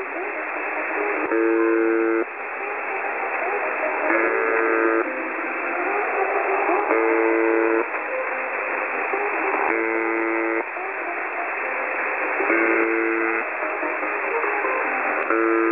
해커가 UVB-76 주파수를 통해
노래